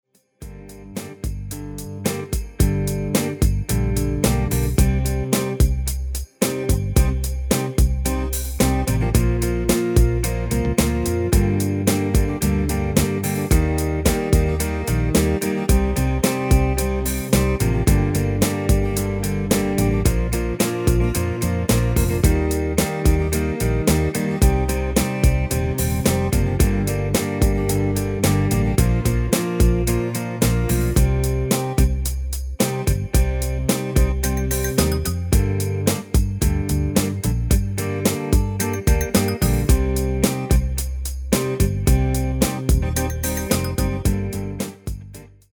Demo/Koop midifile
Genre: Nederlandse artiesten pop / rock
Toonsoort: G
- Géén vocal harmony tracks
Demo's zijn eigen opnames van onze digitale arrangementen.